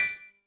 gift-collectible.mp3